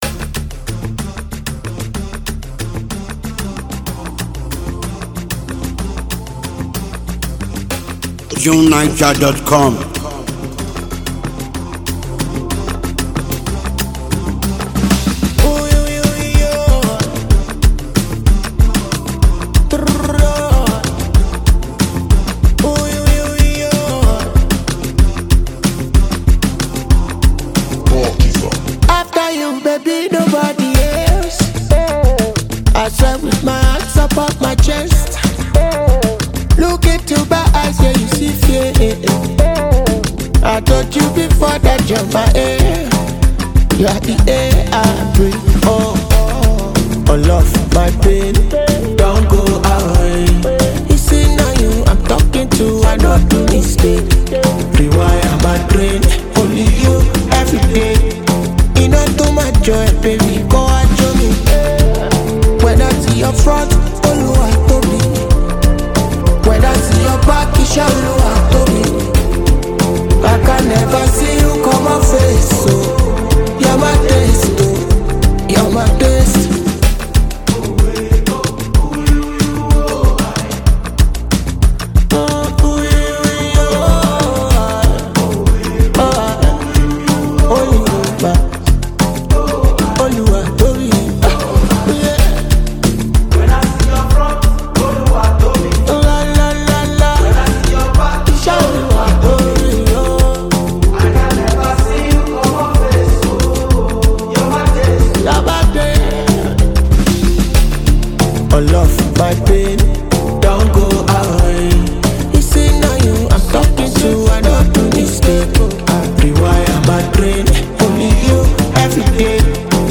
captivating and inventive thumping ballad